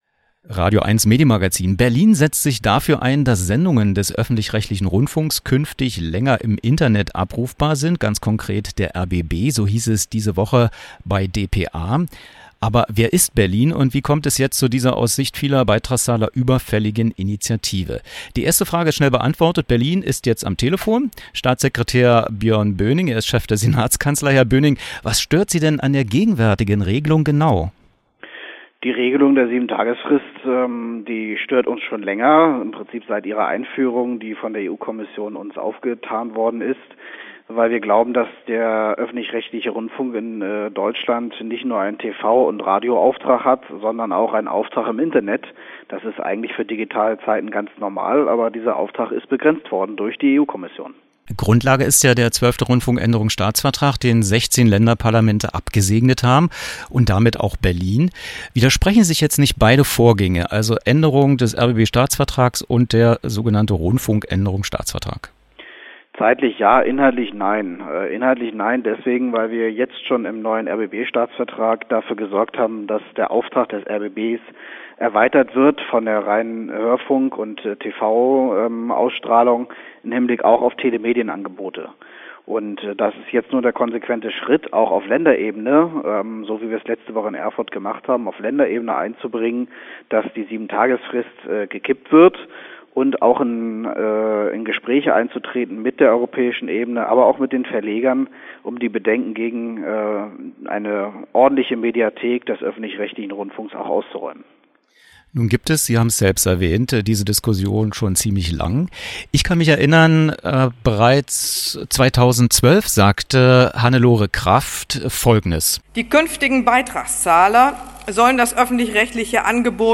Wer: Björn Böhning, Chef der Senatskanzlei, Berlin
Was: Telefoninterview zu Korrekturen am rbb-Staatsvertrag